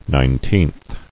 [nine·teenth]